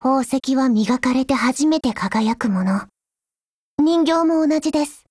贡献 ） 协议：Copyright，其他分类： 分类:少女前线:史蒂文斯520 、 分类:语音 您不可以覆盖此文件。